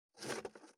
527厨房,台所,野菜切る,咀嚼音,ナイフ,調理音,まな板の上,料理,
効果音厨房/台所/レストラン/kitchen食器食材